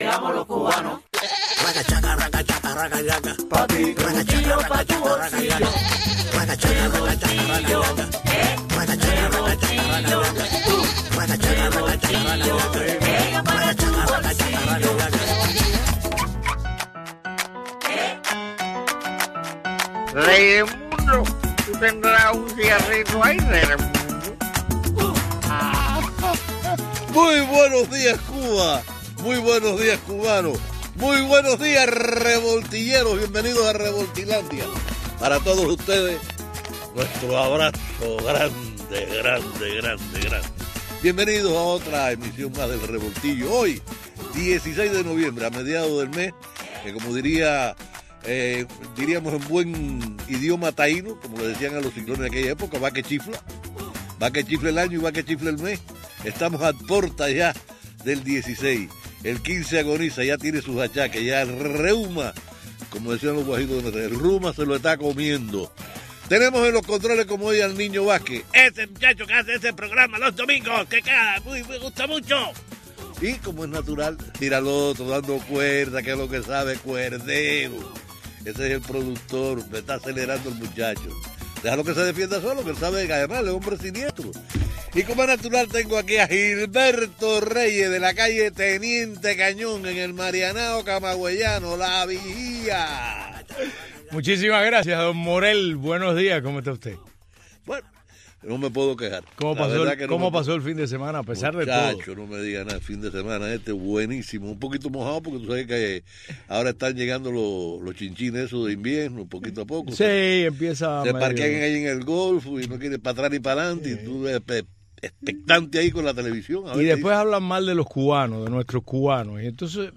programa matutino